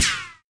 q3rally/baseq3r/sound/weapons/machinegun/ric2.ogg at 981c2a91c8b1b3a1f6034f92e60a9c7afcad4ce6